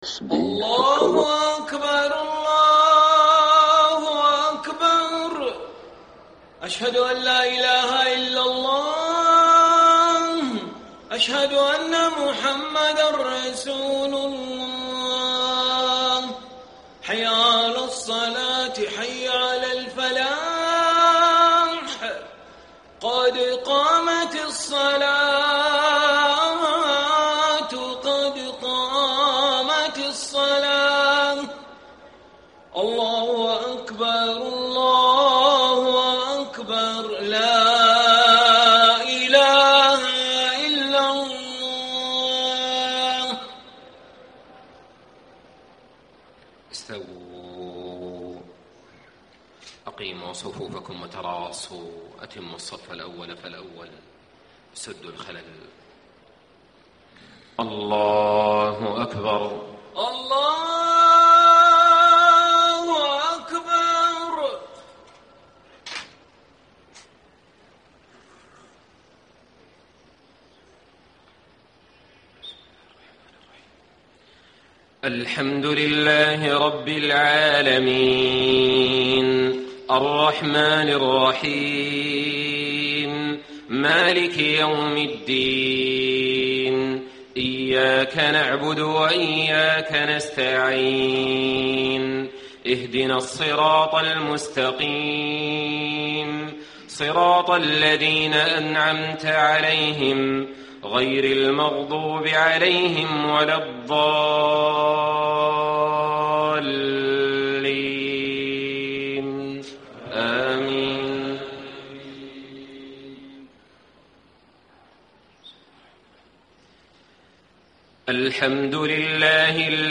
صلاة العشاء 6-6-1435 ما تيسر من سورة المؤمنون > 1435 🕋 > الفروض - تلاوات الحرمين